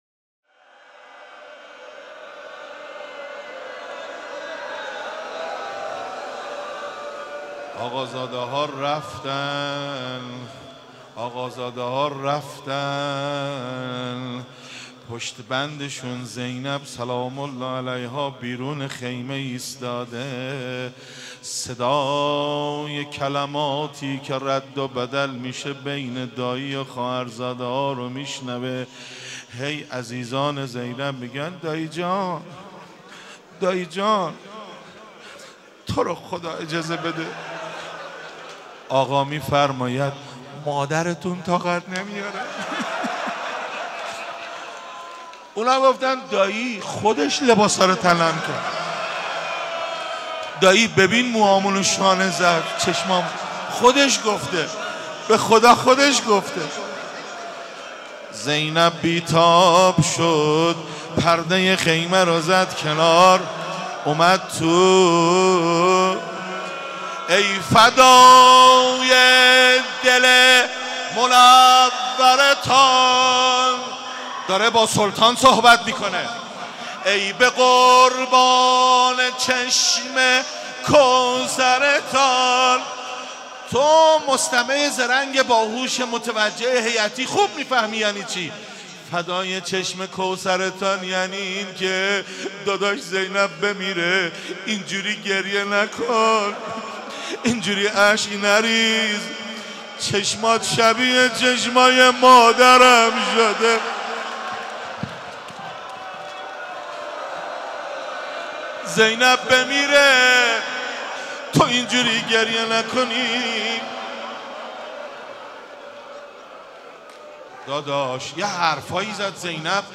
عقیق: مراسم شب پنجم محرم با حضور خیل عزاداران حسینی در مسجد امیر برگزارشد.
صوت مراسم
قسمت دوم - ( روضه )